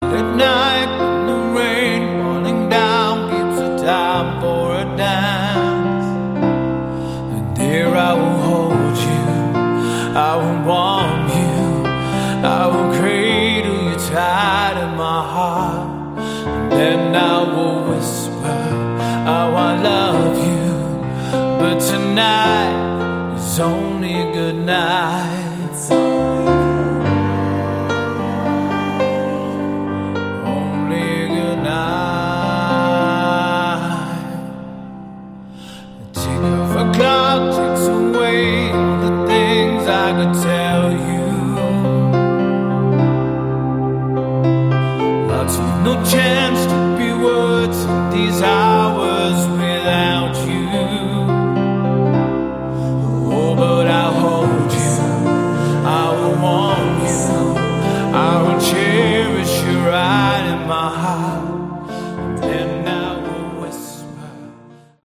Category: AOR/Melodic Rock
It's simply good, straight ahead rock.